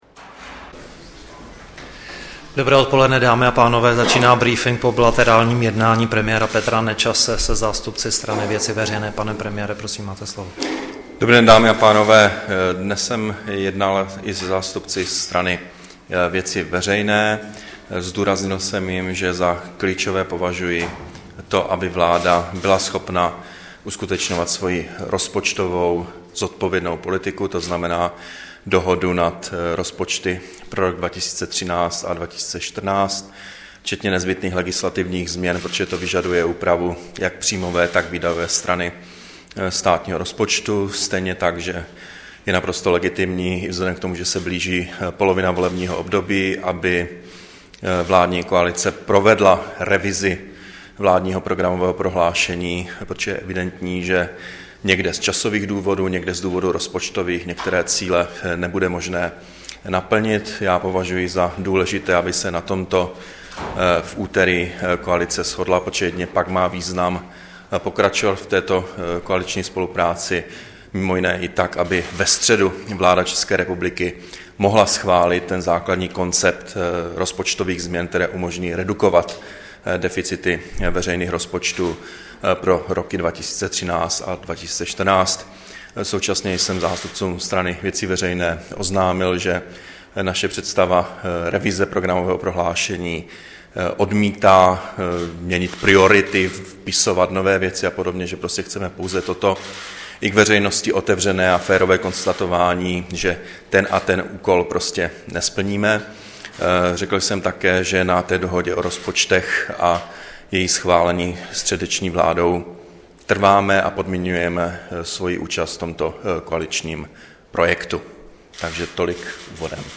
Brífink po jednání premiéra Nečase se zástupci VV, 5. dubna 2012